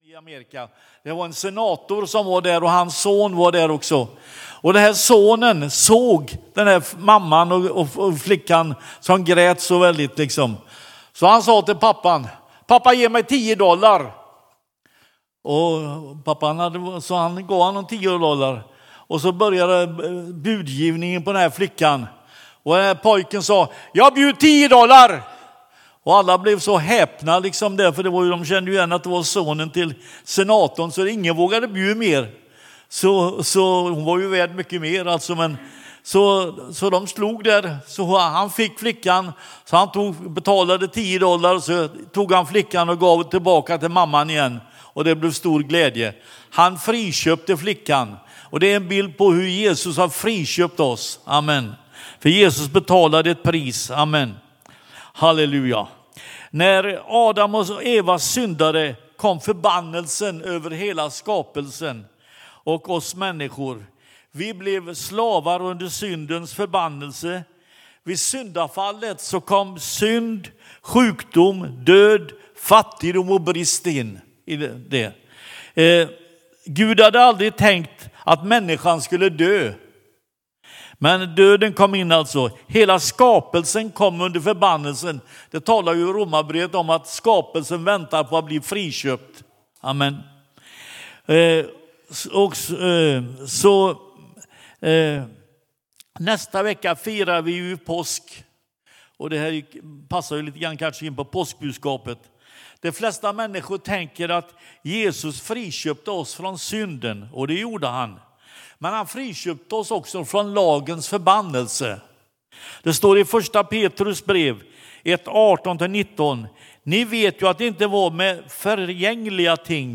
Pingstkyrkan Eksjö söndag 29 mars 2026